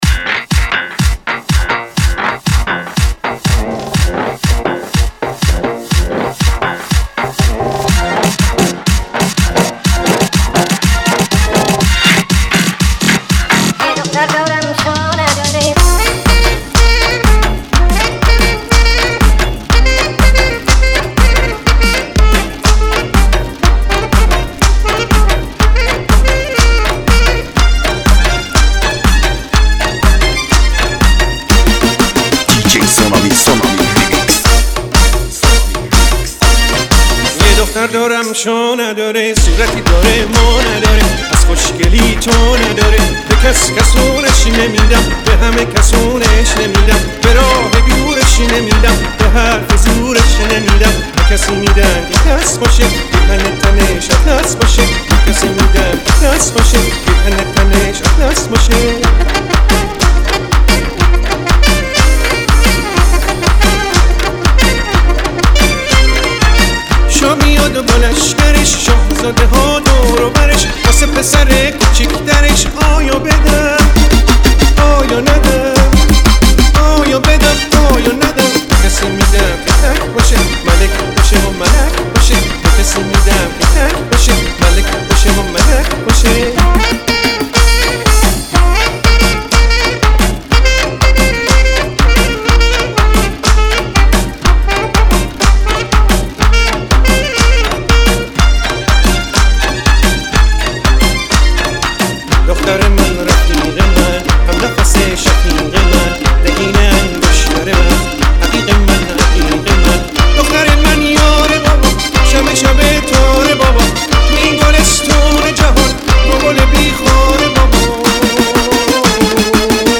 اهنگ شاد ایرانی
ریمیکس شاد